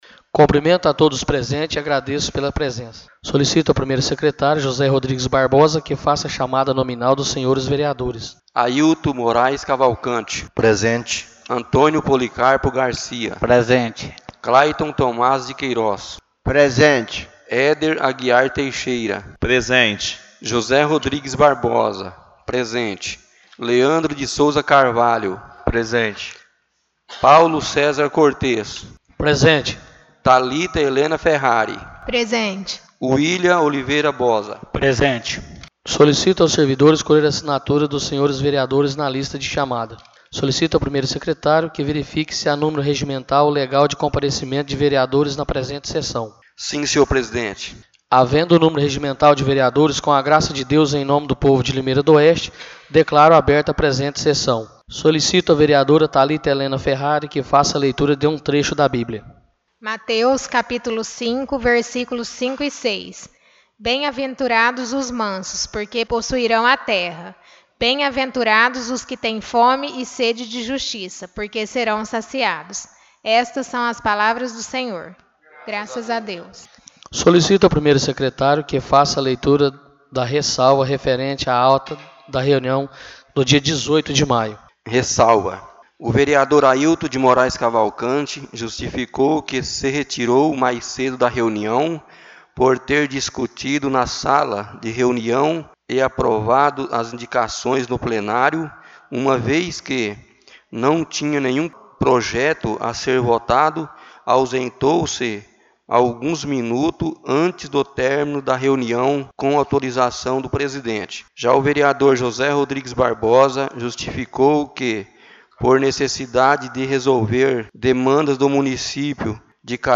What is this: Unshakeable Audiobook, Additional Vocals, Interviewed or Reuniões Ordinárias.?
Reuniões Ordinárias.